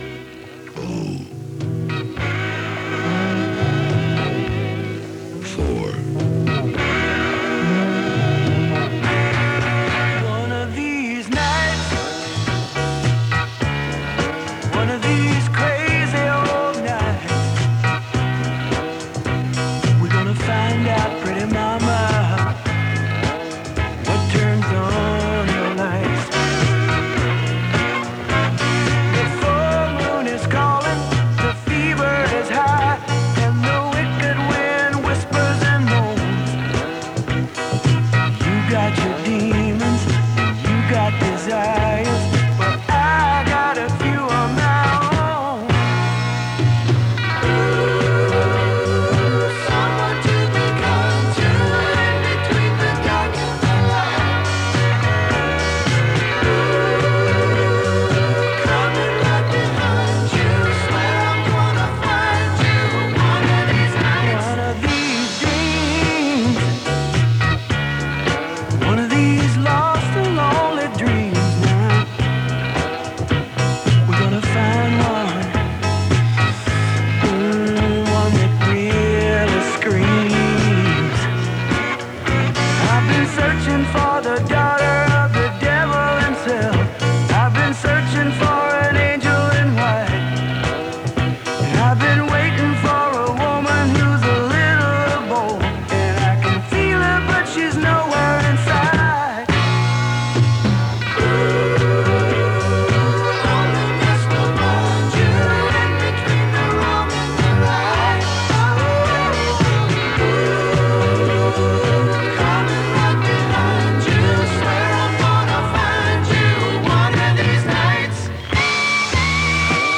This recording from 104MHz in stereo